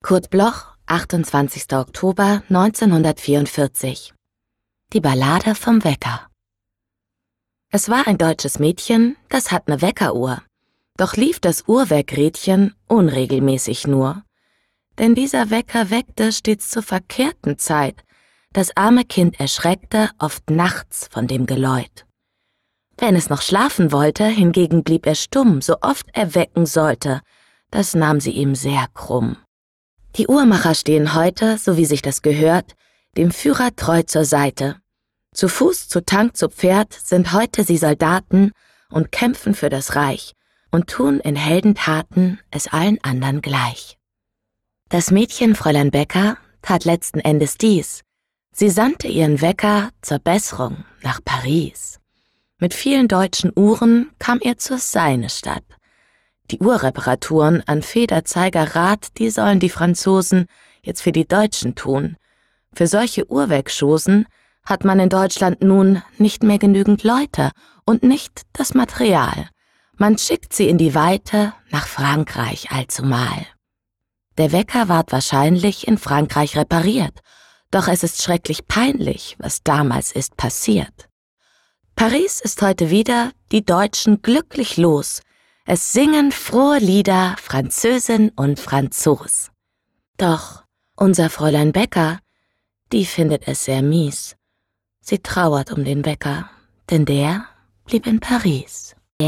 aufgenommen im Tonstudio Kristen & Schmidt, Wiesbaden